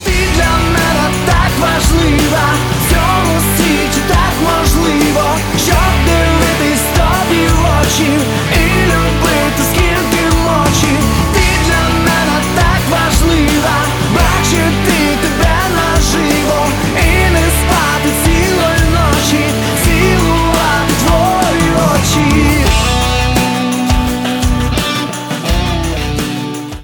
• Качество: 192, Stereo
Красивая романтическая украинская песня посвящённая девушке